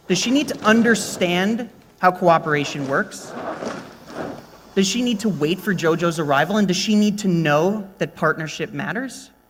En las siguientes grabaciones podemos observar este caso especial de asimilación en el que se produce un cambio tanto en el punto de articulación como en la sonoridad (la /z/ se convierte en /ʃ/).
Como se aprecia claramente, debido a la asimilación, la /z/ prácticamente desaparece y —por mucho que digan algunos libros  especializados— no queda ningún rastro de un sonido /ʒ/, por lo que puede resultar bastante difícil comprender las palabras que el hablante está pronunciando.